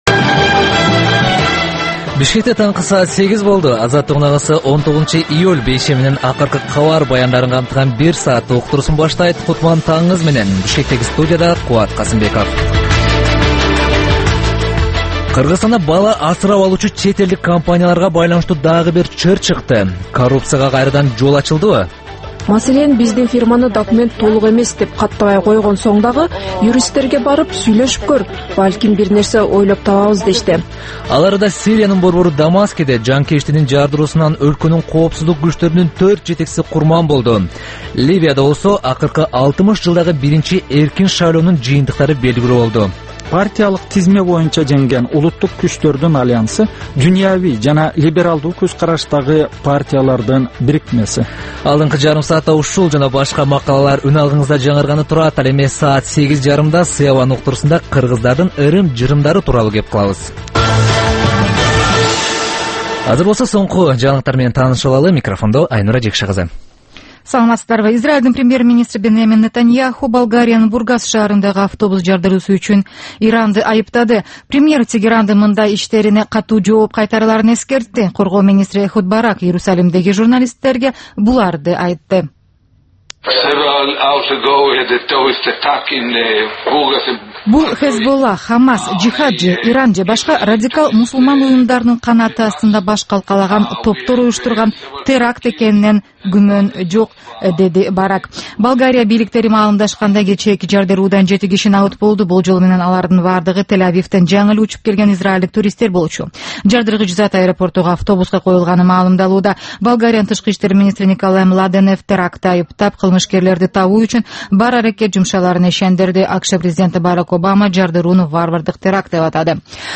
Бул таңкы үналгы берүү жергиликтүү жана эл аралык кабарлар, ар кыл орчун окуялар тууралуу репортаж, маек, талкуу, баян, күндөлүк басма сөзгө баяндама жана башка берүүлөрдөн турат. "Азаттык үналгысынын" бул таңкы берүүсү Бишкек убакыты боюнча саат 08:00ден 08:30га чейин обого чыгарылат.